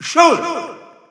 Announcer pronouncing Shulk's name in French.
Category:Shulk (SSBU) Category:Announcer calls (SSBU) You cannot overwrite this file.
Shulk_French_Announcer_SSBU.wav